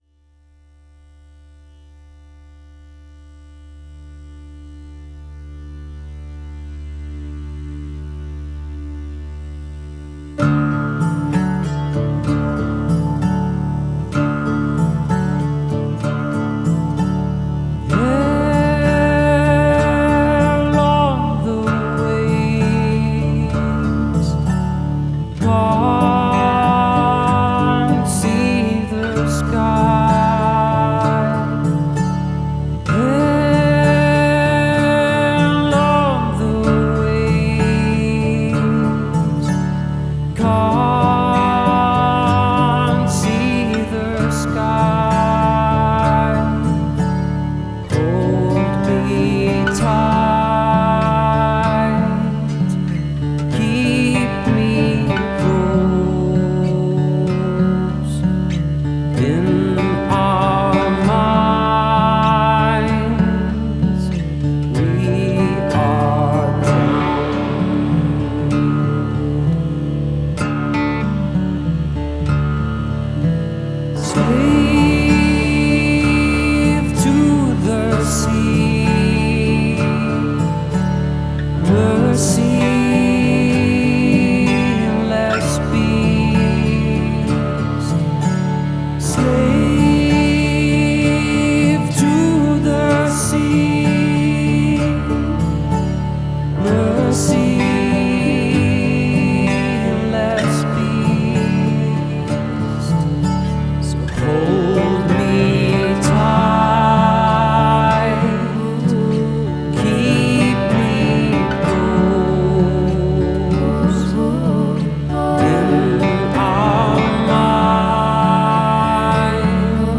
Genre: Indie Rock/ Indie Folk